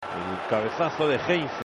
エル・カベサッソ・デ・＊＊＊＊＊と言っていて、星の部分が選手の名前になっている」